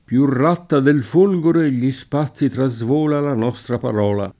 folgore [f1lgore] s. f. («fulmine») — antiq. o poet. come s. m.: Più ratta del folgore Gli spazi trasvola La nostra parola [